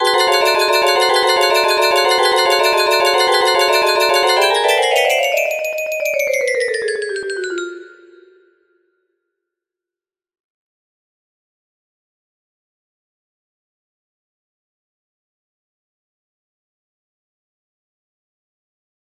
Yay! It looks like this melody can be played offline on a 30 note paper strip music box!